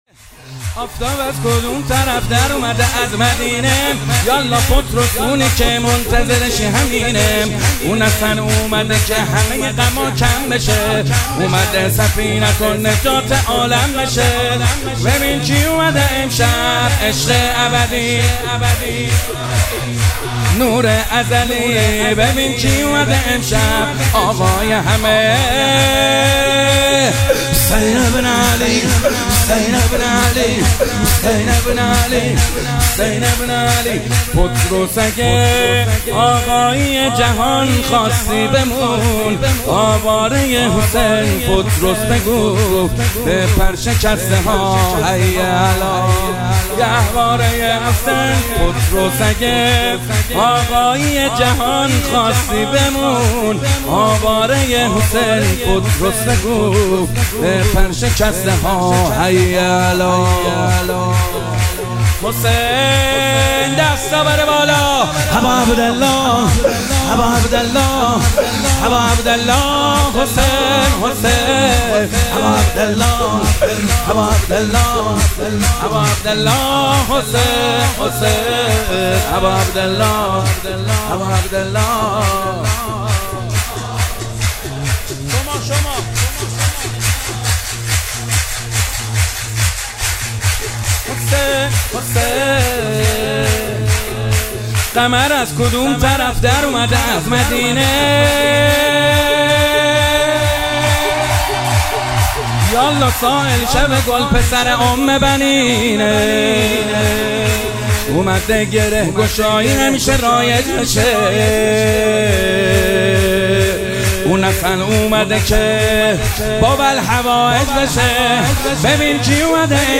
شب ولادت امام سجاد ع - سرود - آفتاب از کدوم طرف در اومده از مدینه - محمد حسین حدادیان
شب ولادت امام سجاد علیه السلام